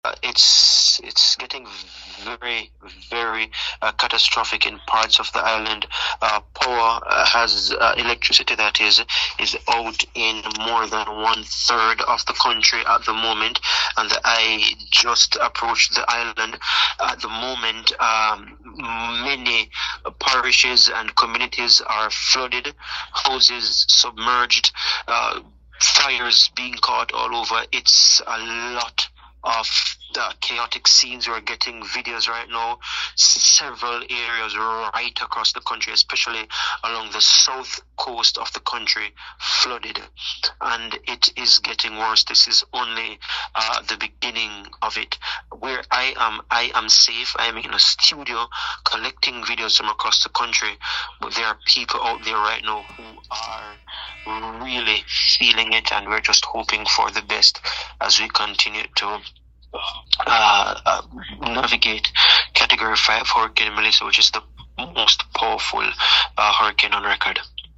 Esto es solo el principio“, aseguró mediante llamada telefónica.